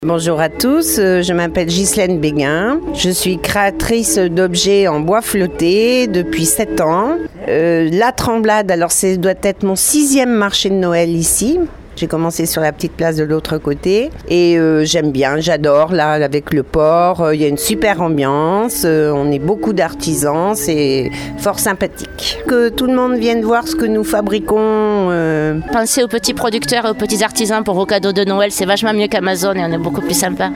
Je vous propose à présent d’aller à la rencontre de ces producteurs et artisans qui font la fierté du Marché de Noël de La Tremblade :